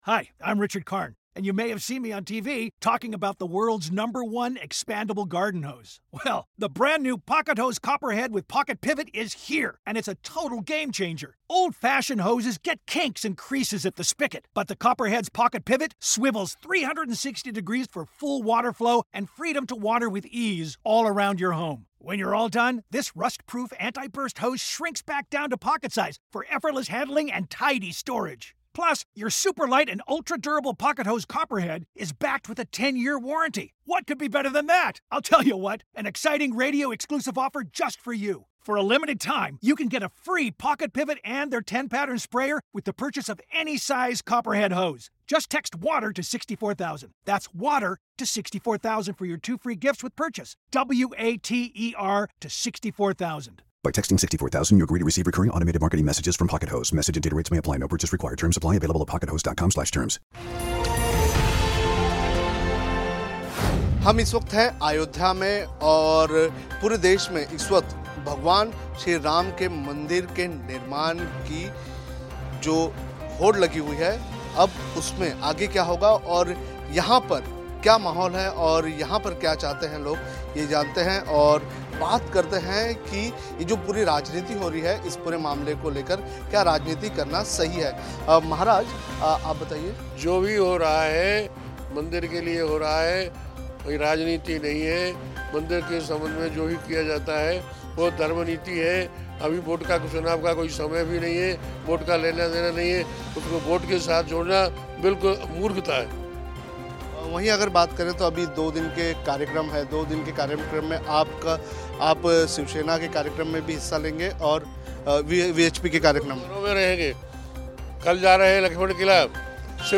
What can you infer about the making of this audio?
HW Live from Ground Zero Ayodhya: Hearing it straight from the on-ground priest there